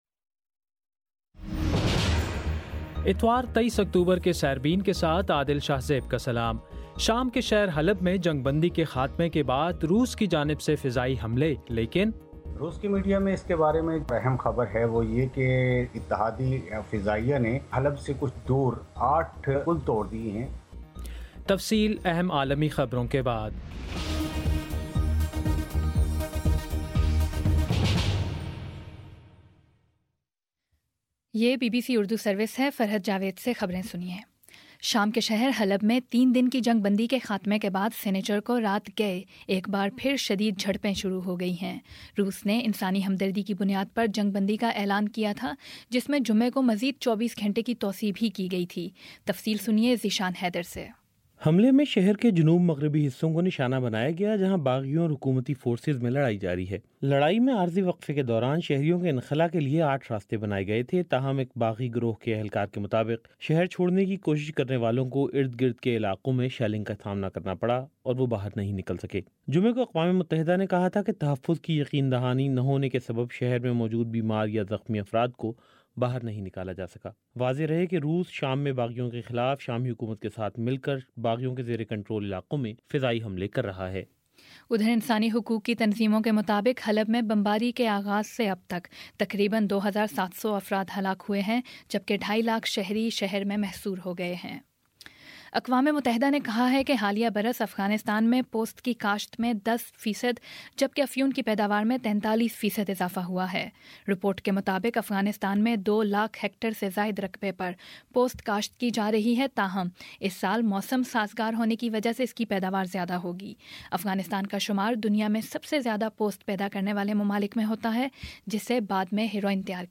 اتوار23 اکتوبر کا سیربین ریڈیو پروگرام